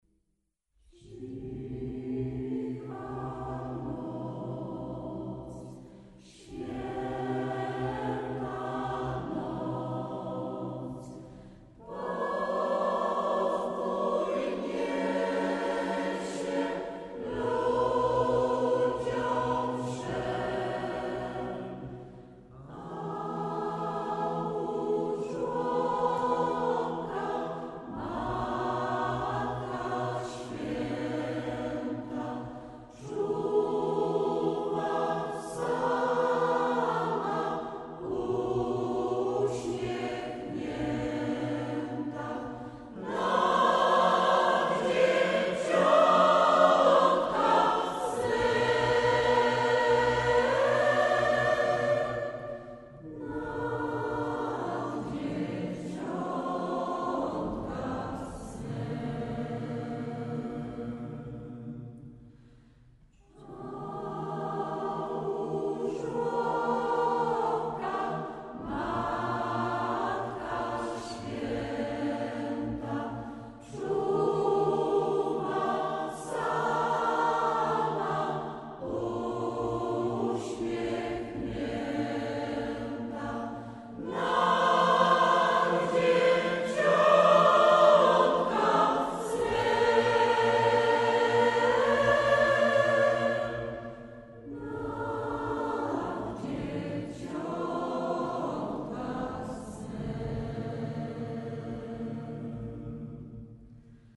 Kolędy - do pobrania - pliki mp3 - fragment utworów z II płyty